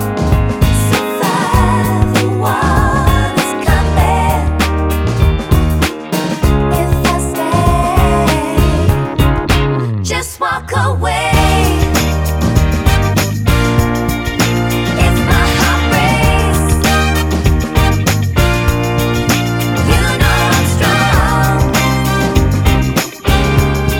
Soundtracks